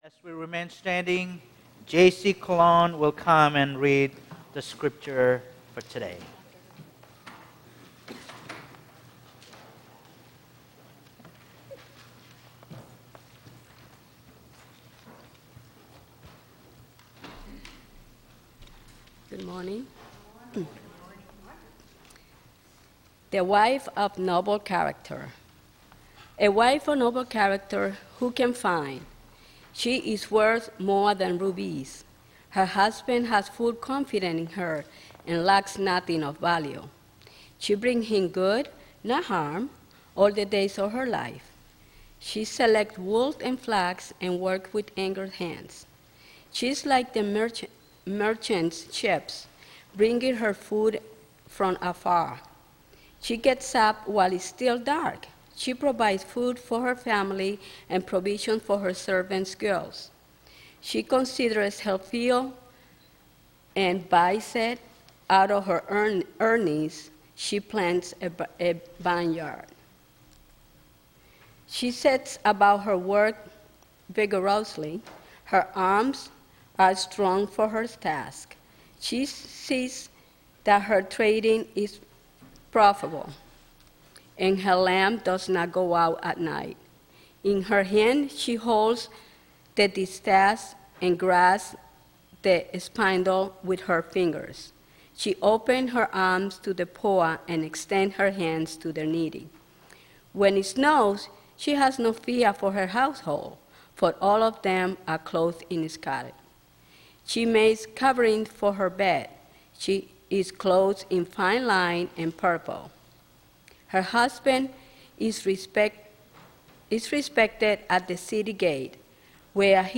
The audio recording of our latest Worship Service is now available.